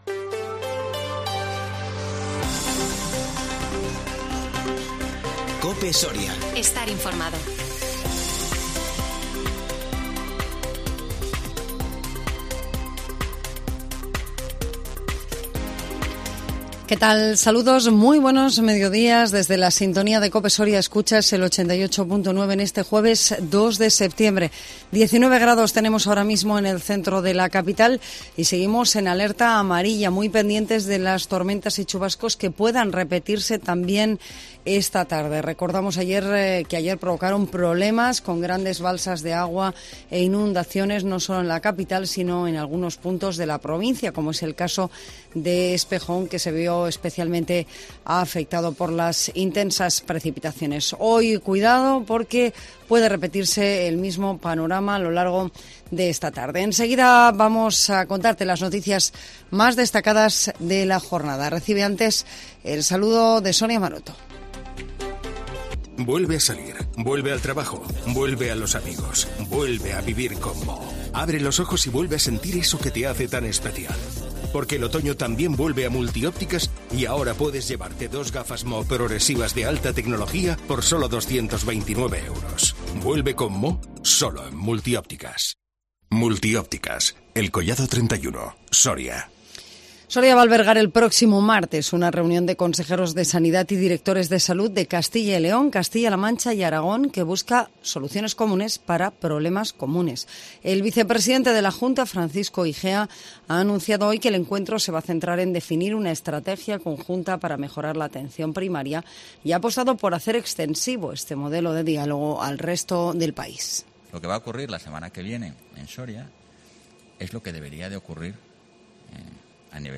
INFORMATIVO MEDIODÍA 2 SEPTIEMBRE 2021